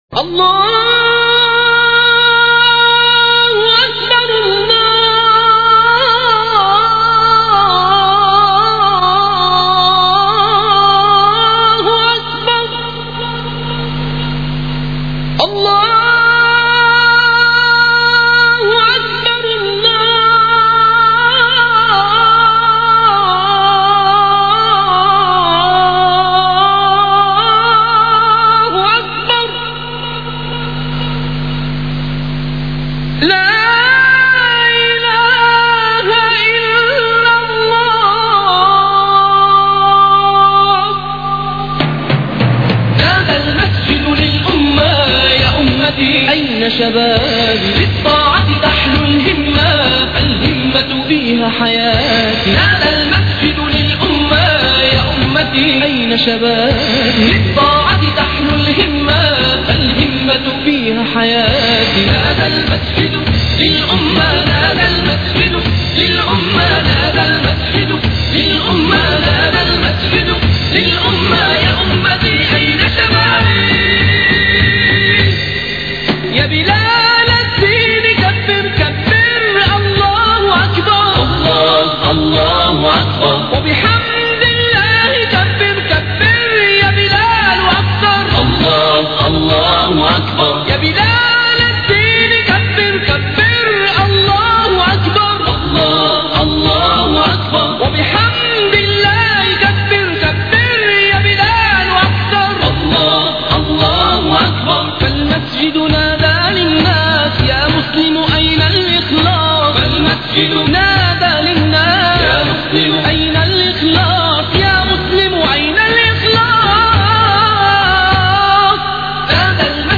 Azan.mp3